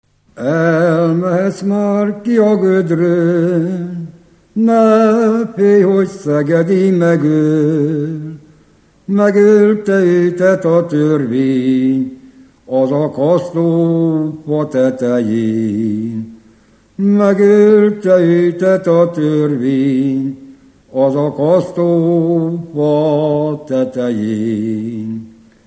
Erdély - Kolozs vm. - Váralmás
ének
Műfaj: Ballada
Stílus: 4. Sirató stílusú dallamok
Szótagszám: 8.8.8.8
Kadencia: 5 (4) 1 1